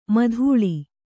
♪ madhuḷi